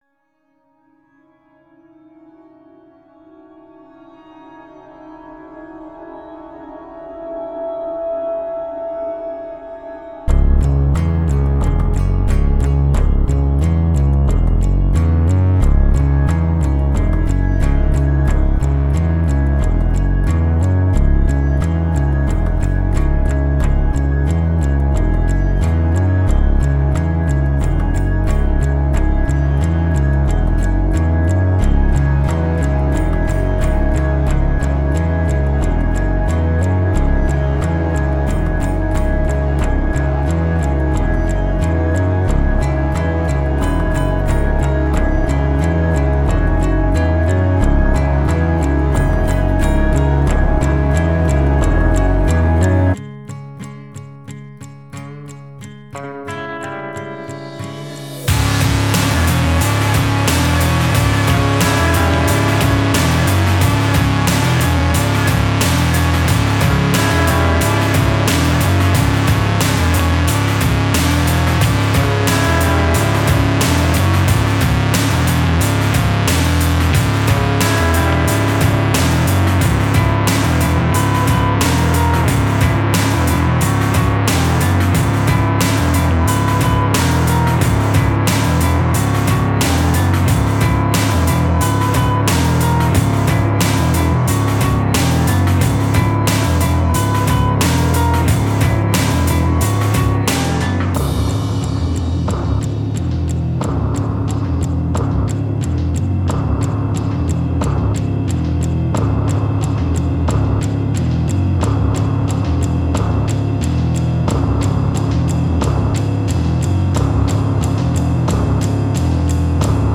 Жанр: Electronica